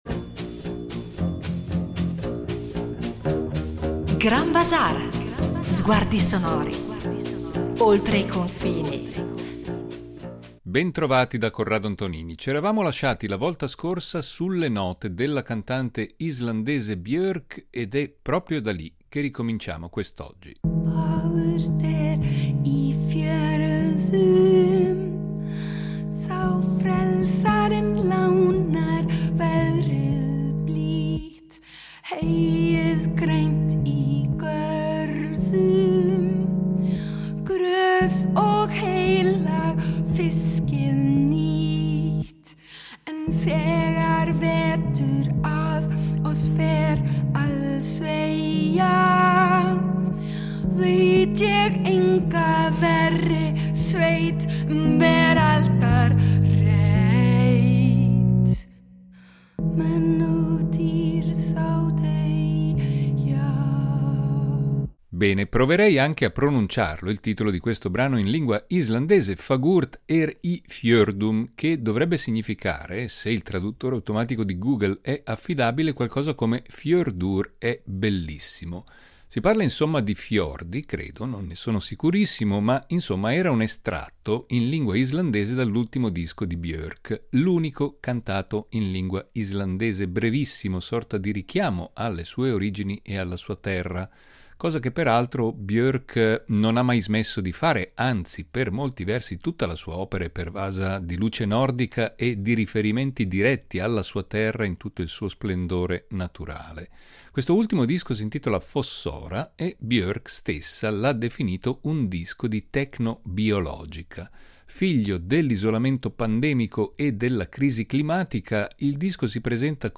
Il grande freddo , più che un auspicio o una reminiscenza nostalgica , sarà il tentativo di evocare le melodie e i canti coi quali le popolazioni del Grande Nord da sempre affrontano il rigore delle stagioni a quelle latitudini. Islanda, Groenlandia, il Canada, la Lapponia, la Siberia, la Mongolia…